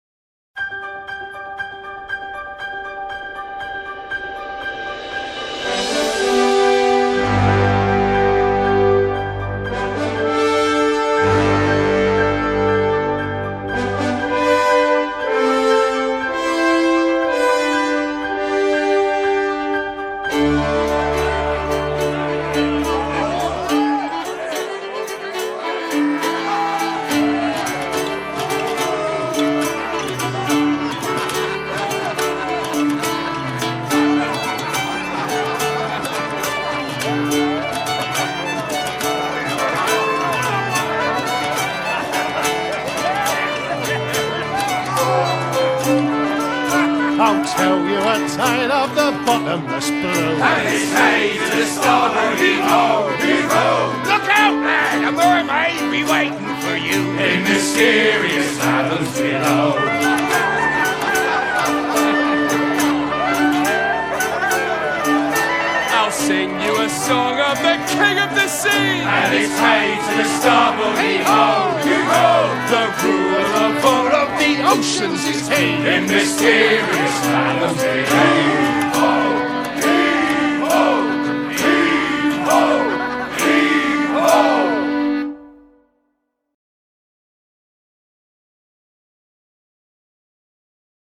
Soundtrack, Pop